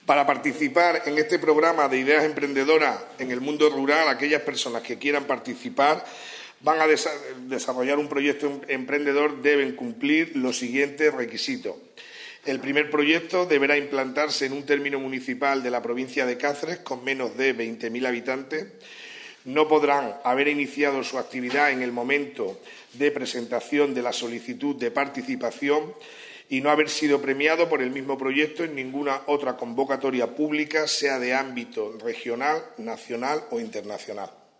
CORTES DE VOZ
El diputado de Desarrollo Local, Samuel Fernández Macarro, ha presentado este jueves en rueda de prensa la segunda edición del Programa de Ideas Emprendedoras en el Mundo Rural.